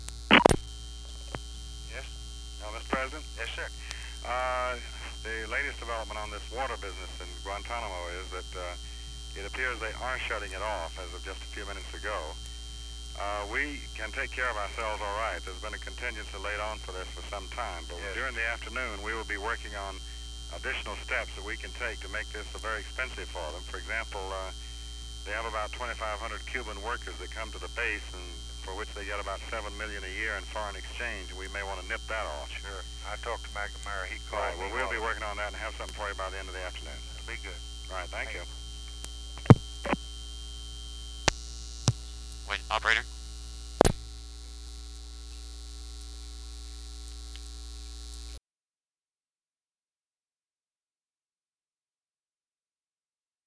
Conversation with DEAN RUSK, February 6, 1964
Secret White House Tapes